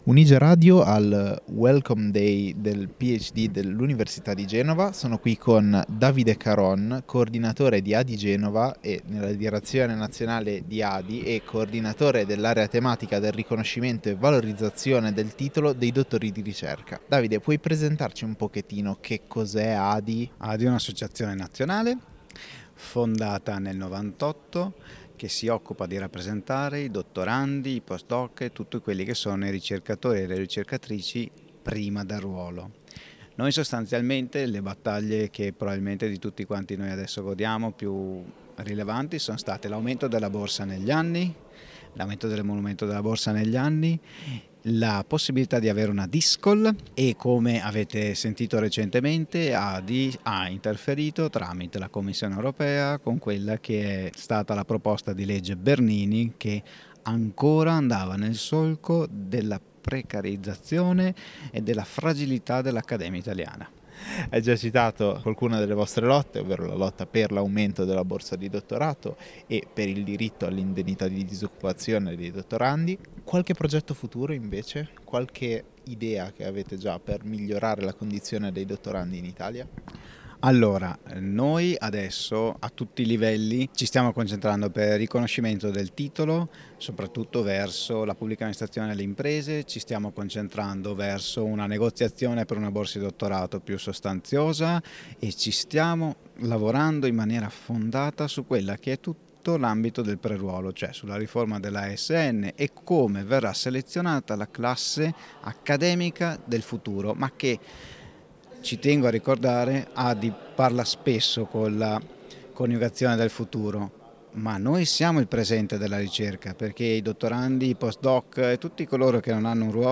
Un’intervista che ribadisce un messaggio chiave: i dottorandi non sono il futuro, ma il presente della ricerca.